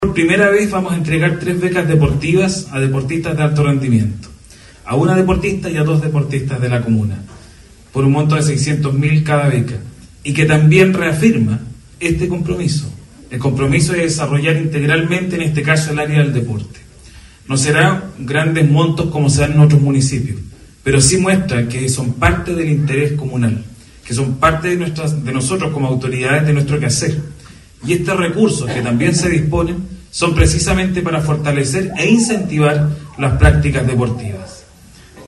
En la ceremonia, el Alcalde Aldo Retamal, manifestó el compromiso del Concejo Municipal para aprobar los recursos e implementar esta Beca Municipal Deportiva, la cual se entrega por primera vez.
CUNA-ALCALDE-BECA-1.mp3